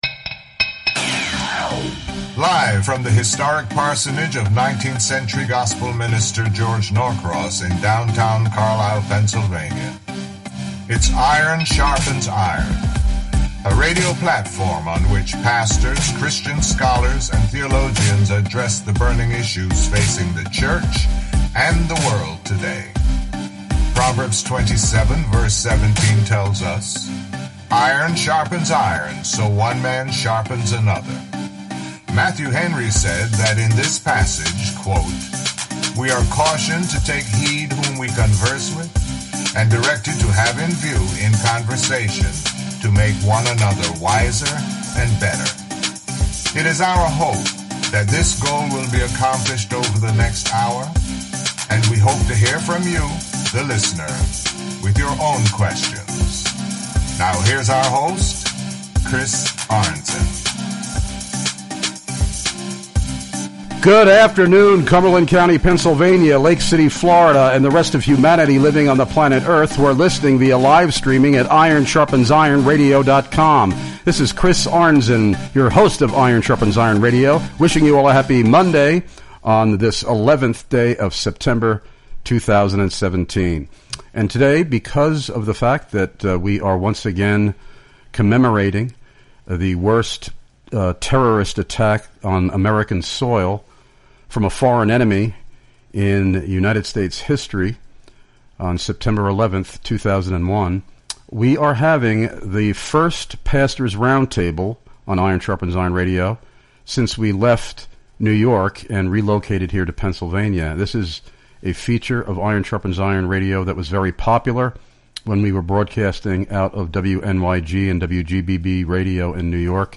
*TODAY* will be our first “IRON SHARPENS IRON” Radio “THE PASTORS’ ROUNDTABLE” since we relocated from NY to PA!!